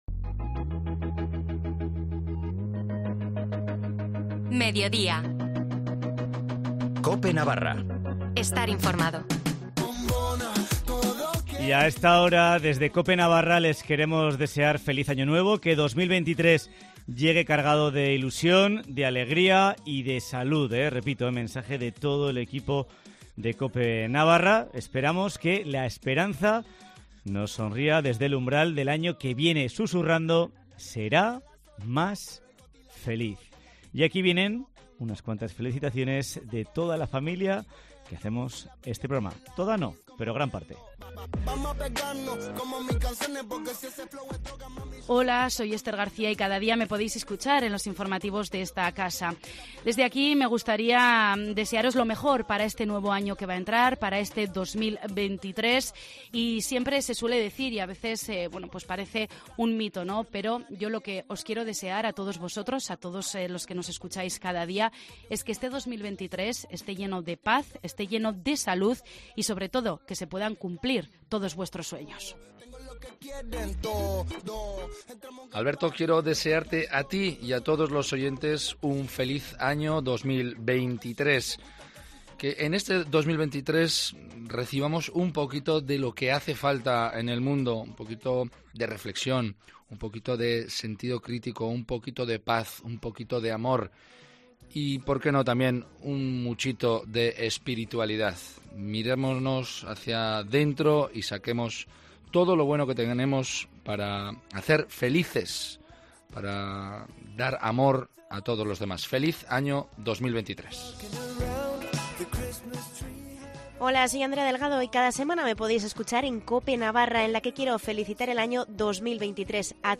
Redactores y colaboradores de COPE Navarra se unen para pedir los deseos para el 2023